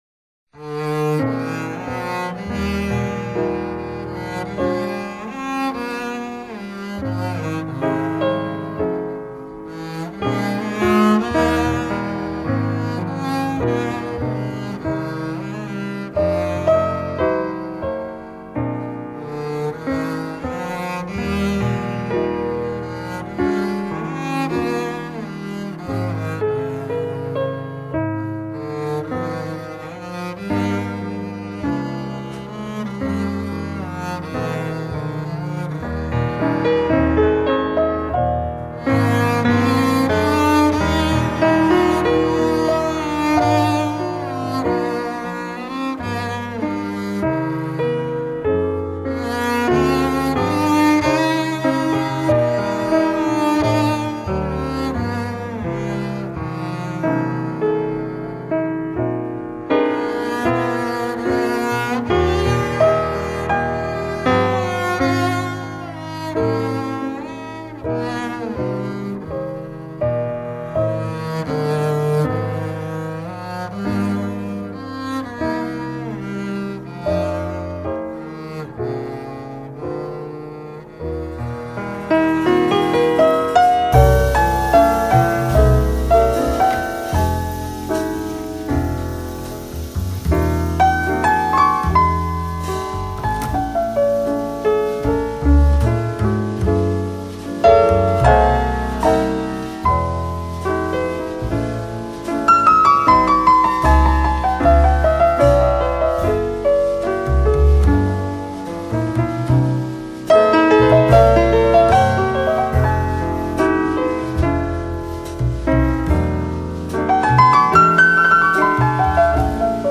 囊括爵士、 民謠等各種樂曲風格